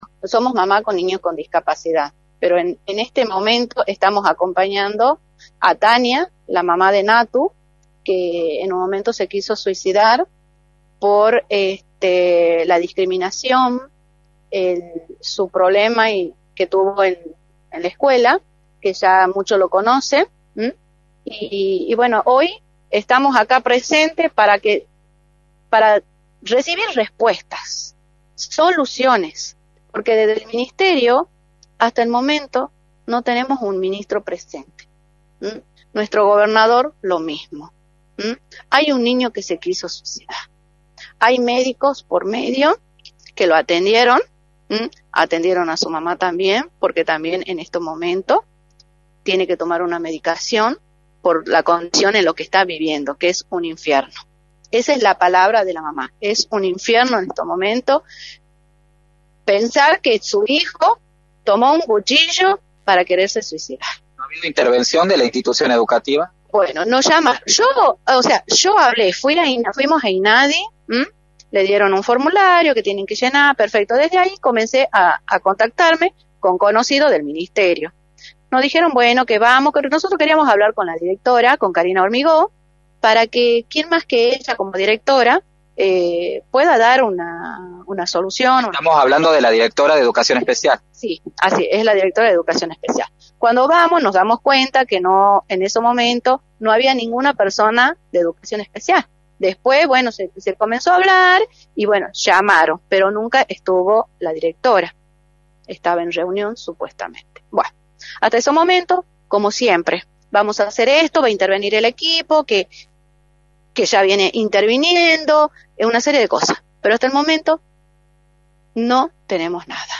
Radio del Plata Tucumán
entrevista